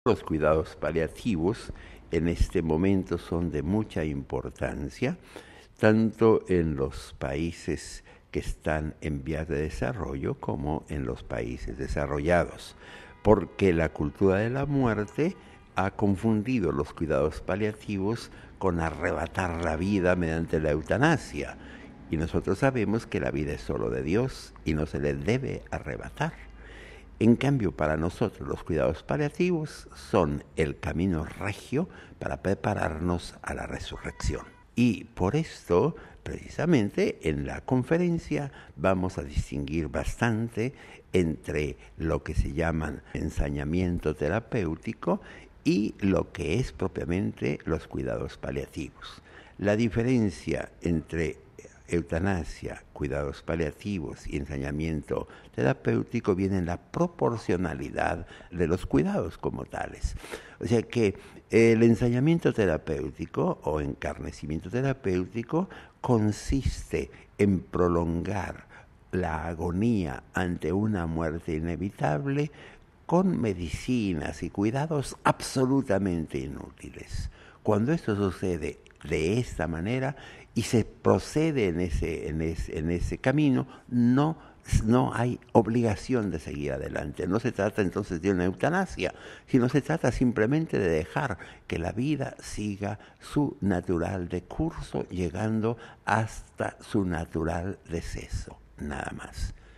La misma Conferencia Internacional se ha propuesto dar una respuesta válida, por parte de la Iglesia católica, ante algunos problemas acuciantes de nuestro tiempo, entre ellos la eutanasia y el ensañamiento terapéutico. Tuvimos ante nuestros micrófonos al cardenal Javier Lozano Barragán, presidente del dicasterio pontificio para la pastoral en el sector sanitario: